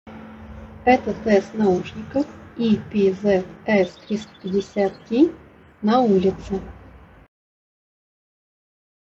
Качество передаваемого звука — среднее уровня.
В шумных условиях:
epz-s350t-na-ulice.mp3